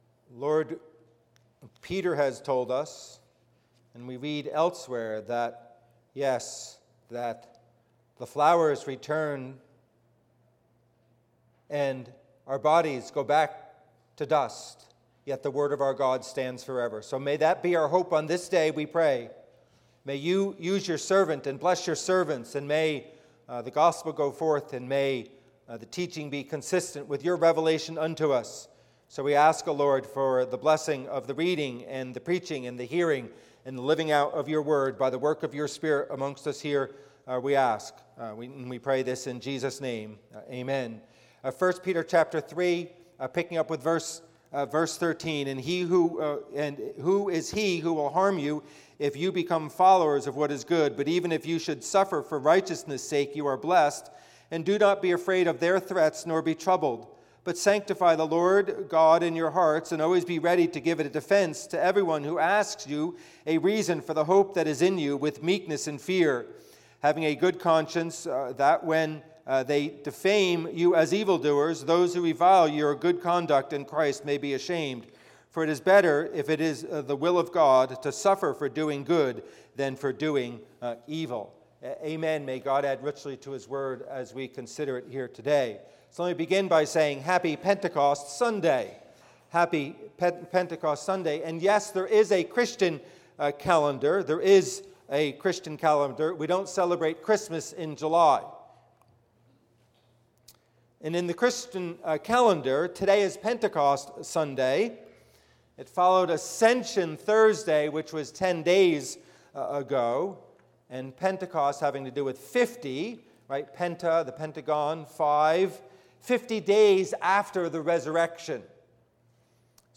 Passage: 1 Peter 3:15 Service Type: Worship Service « If I Die